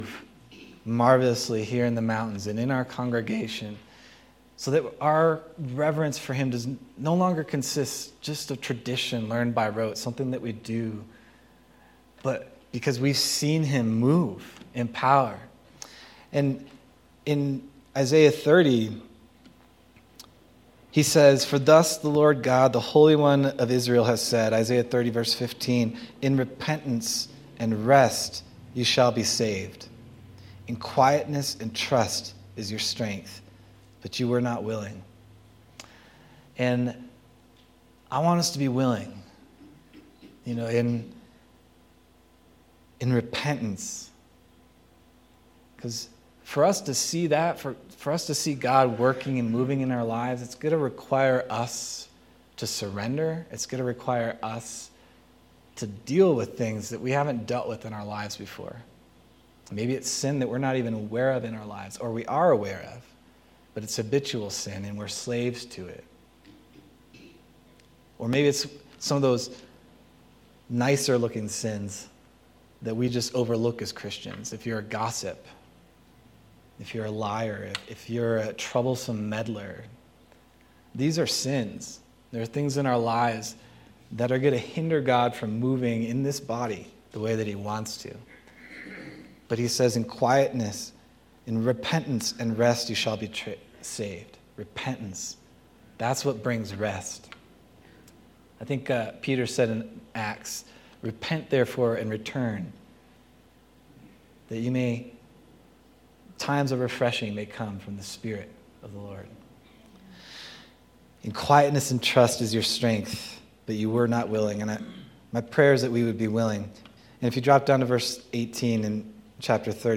July 27th, 2025 Sermon